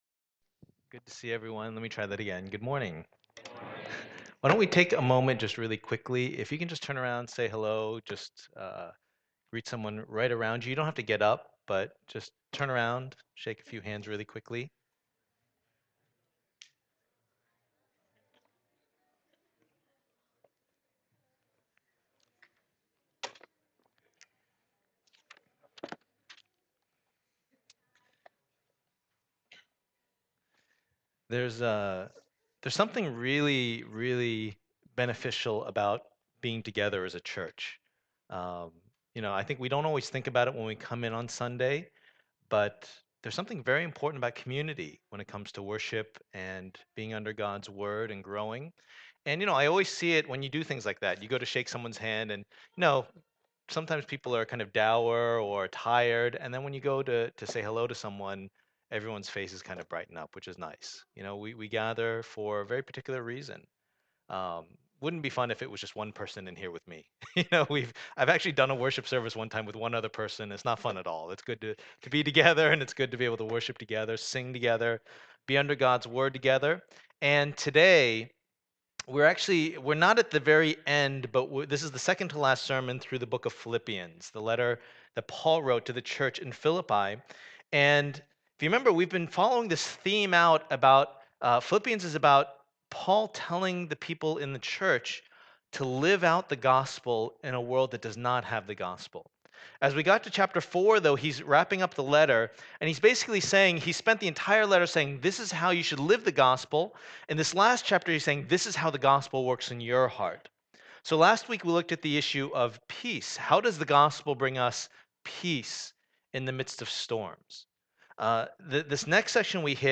Passage: Philippians 4:10-23 Service Type: Lord's Day